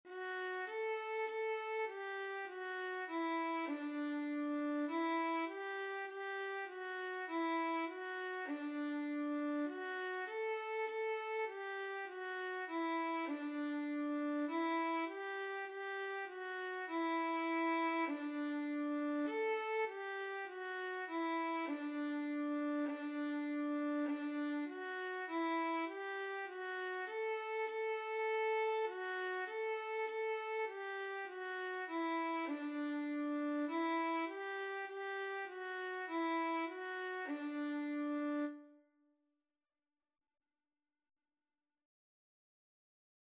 4/4 (View more 4/4 Music)
D5-A5
Beginners Level: Recommended for Beginners
Instrument:
Violin  (View more Beginners Violin Music)
Classical (View more Classical Violin Music)